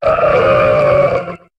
Cri de Wailmer dans Pokémon HOME.